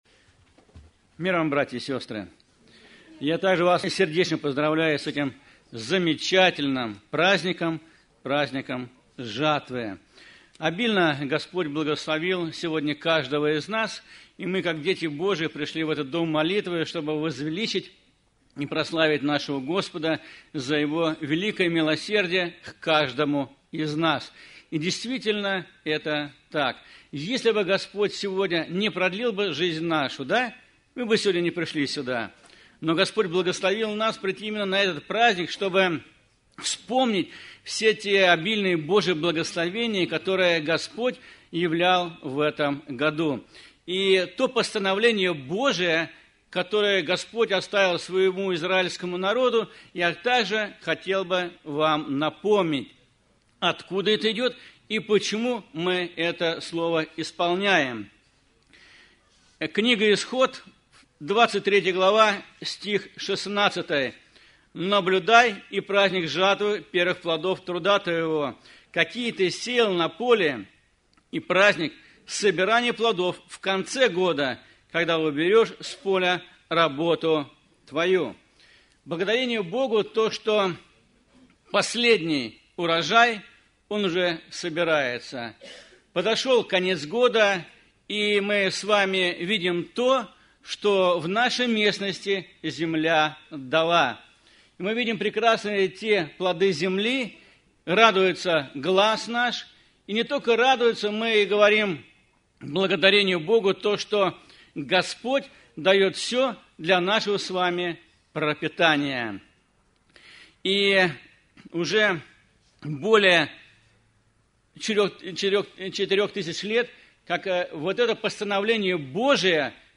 Церковь: Церковь ЕХБ "Голгофа", г. Москва (Местная религиозная организация – Церковь евангельских христиан-баптистов «Голгофа»)